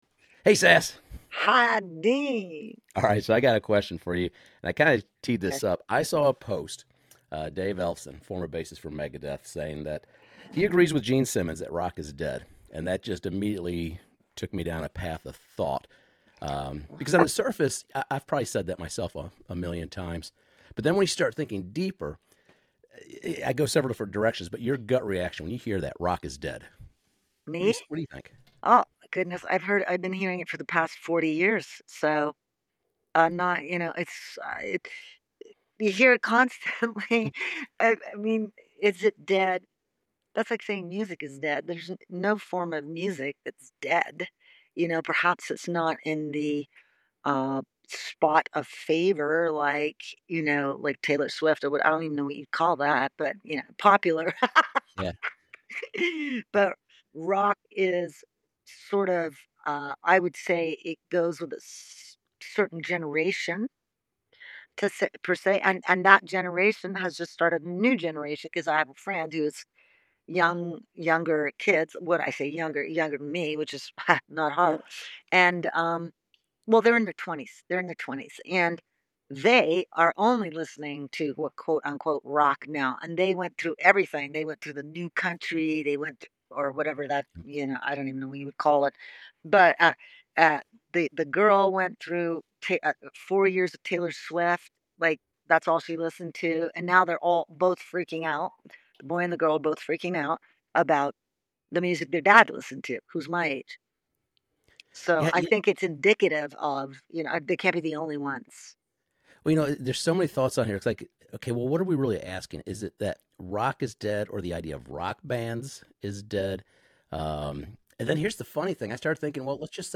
Here is the full un-edited phone call where I explore the topic with songwriter, performer and former Candian Idol judge, Sass Jordan.